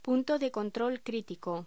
Locución: Punto de control cítrico
voz
Sonidos: Voz humana